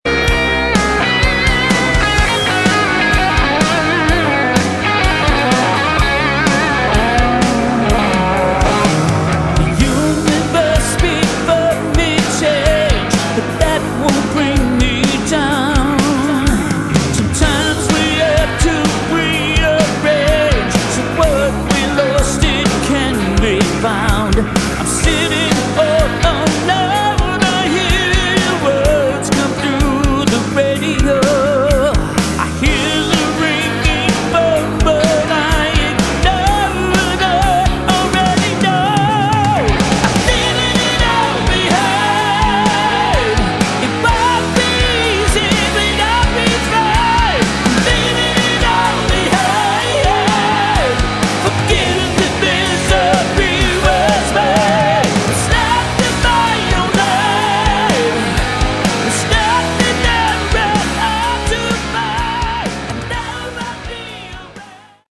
Category: Hard Rock
lead vocals, guitars
lead guitars
bass
drums